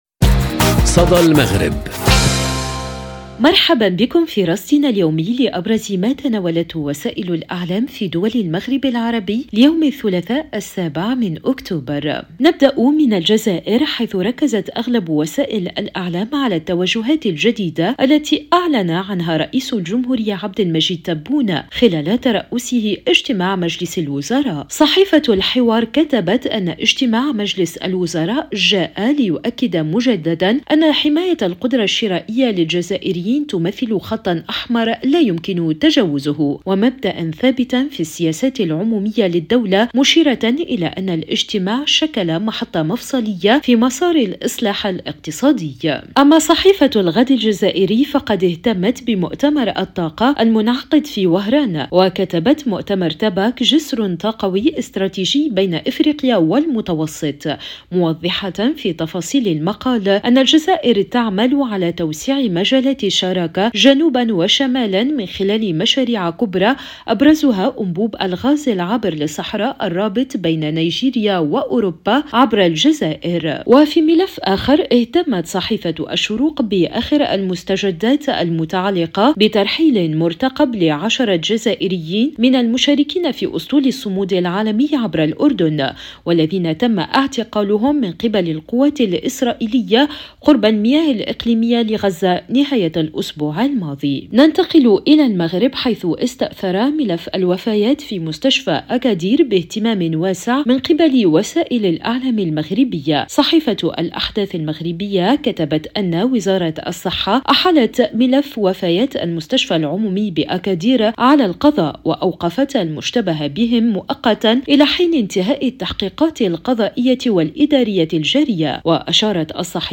صدى المغرب برنامج إذاعي يومي يُبث عبر راديو أوريان إذاعة الشرق، يسلّط الضوء على أبرز ما تناولته وسائل الإعلام في دول المغرب العربي، بما في ذلك الصحف، القنوات التلفزية، والميديا الرقمية.